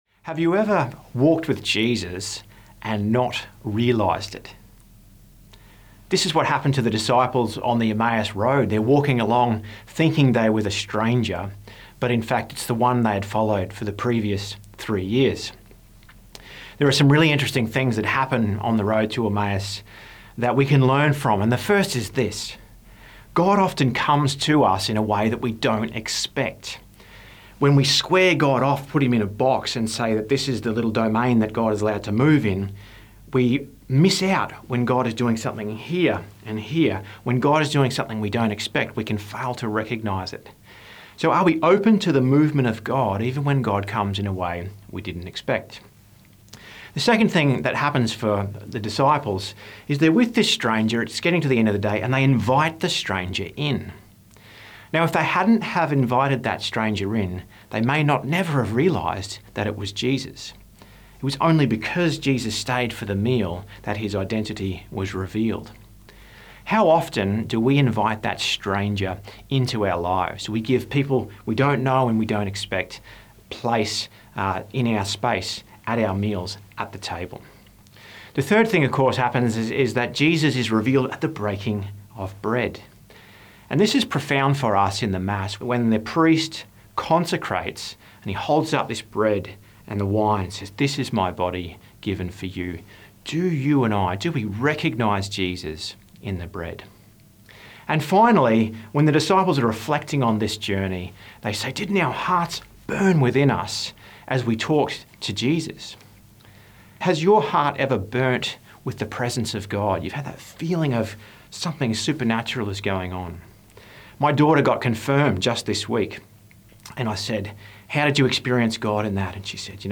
Two-Minute Homily